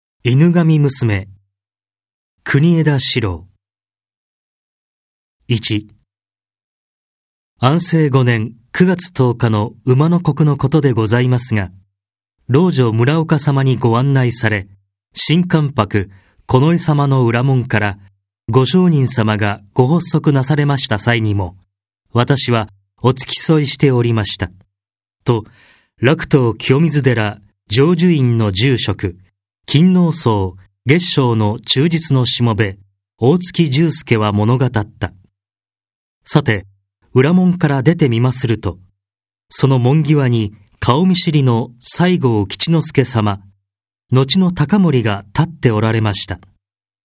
朗読ＣＤ　朗読街道120「犬神娘」国枝史郎
朗読街道は作品の価値を損なうことなくノーカットで朗読しています。